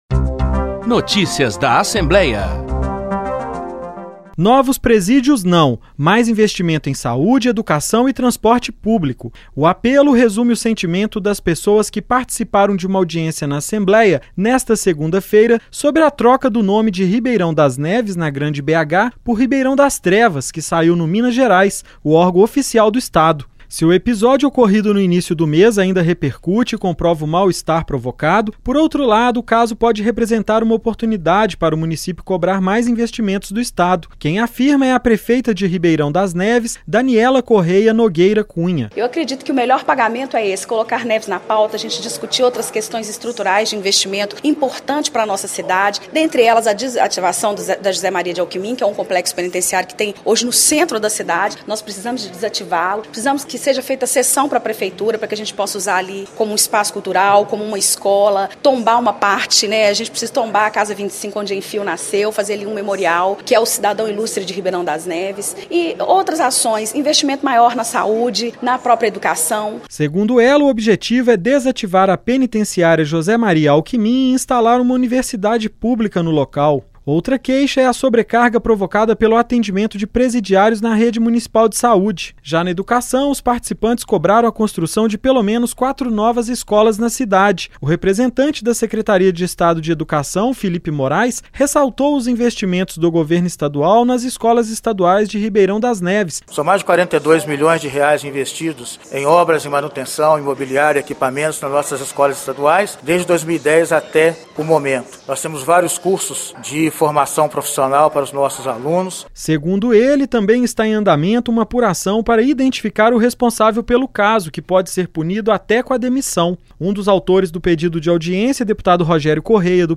Em audiência na ALMG, prefeita da cidade reivindica a desativação de penitenciária para instalação de uma universidade pública no local.